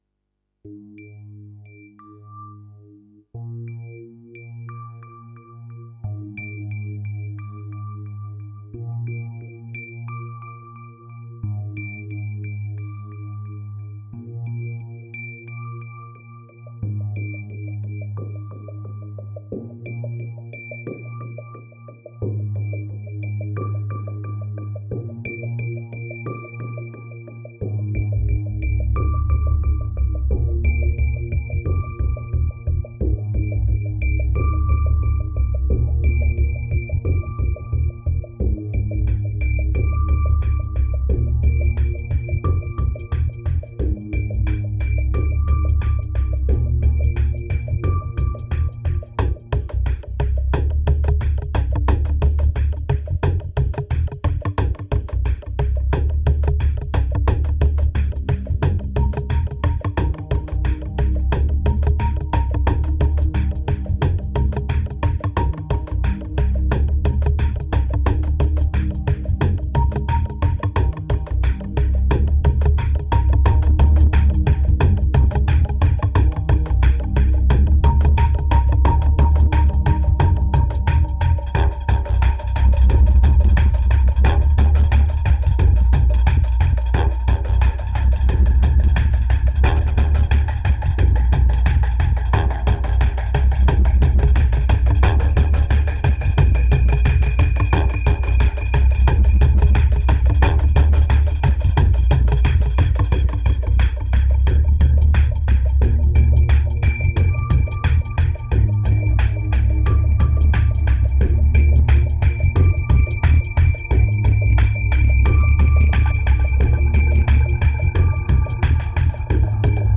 2378📈 - -45%🤔 - 89BPM🔊 - 2011-08-09📅 - -331🌟